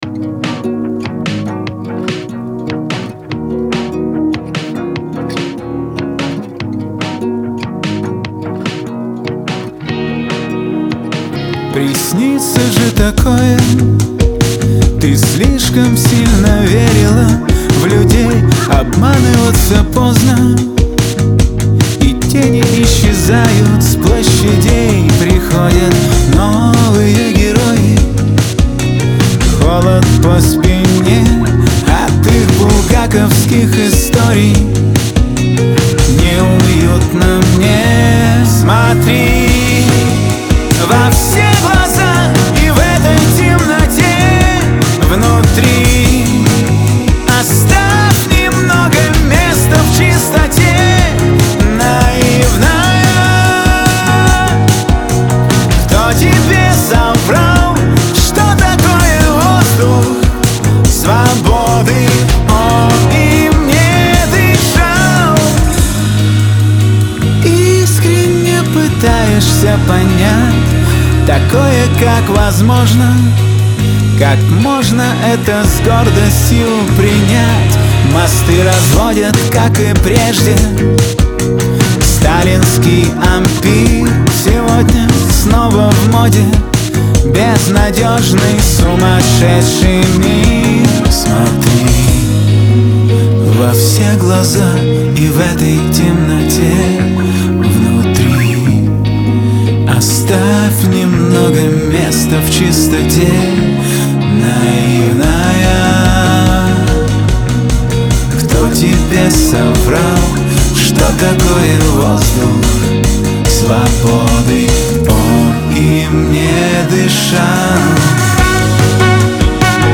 грусть , pop
эстрада
диско